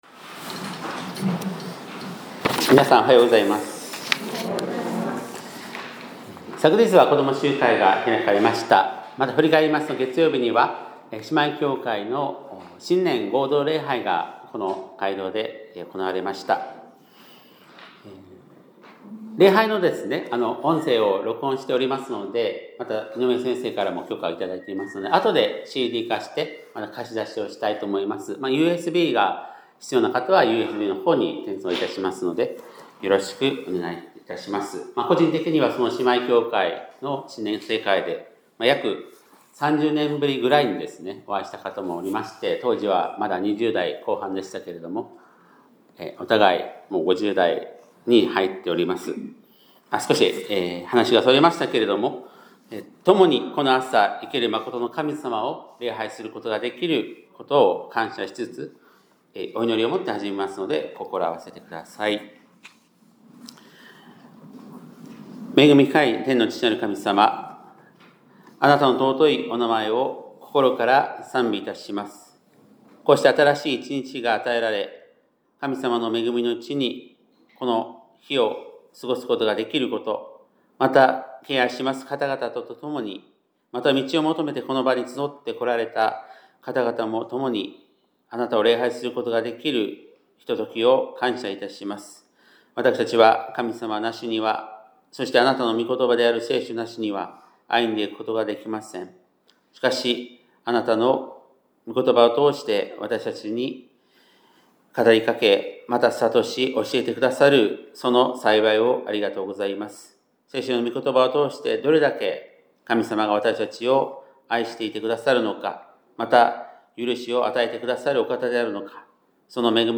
2026年1月18日（日）礼拝メッセージ